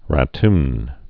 (ră-tn)